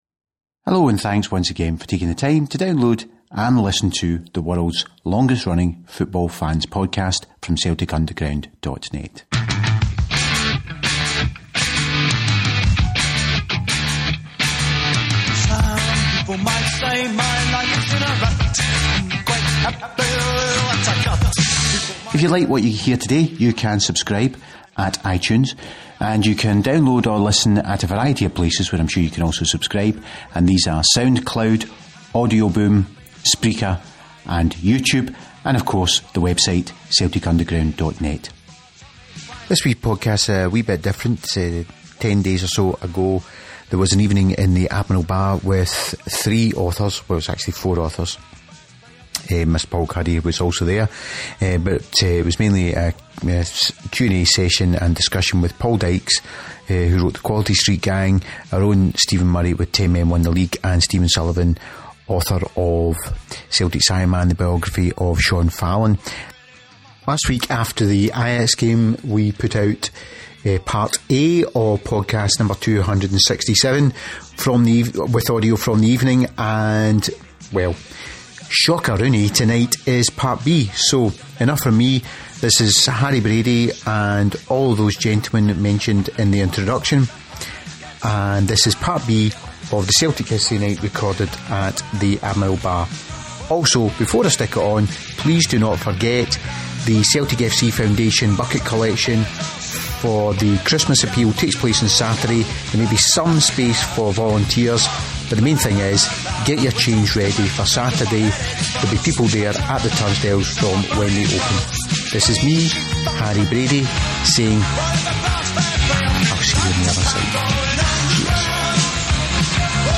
This week we have the second part of the aduio from the Celtic History night which took place in the Admiral Bar on Thursday 19th November.